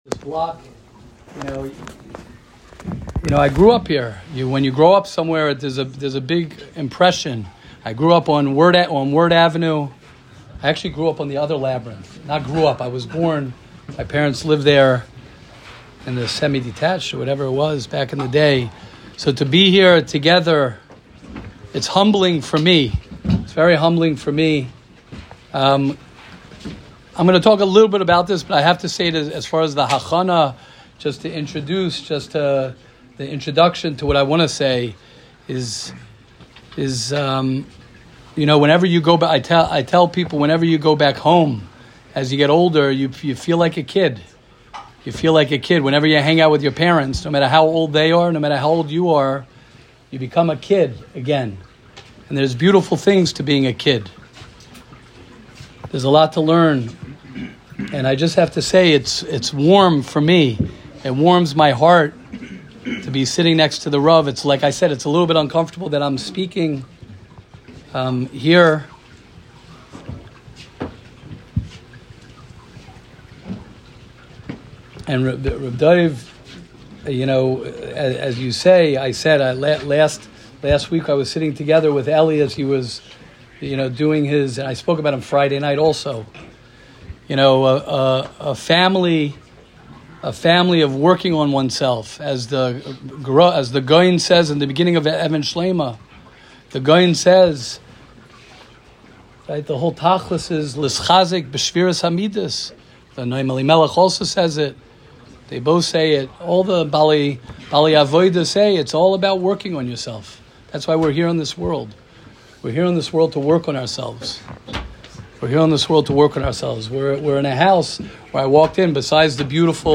Shiur at Leil Shishi in Baltimore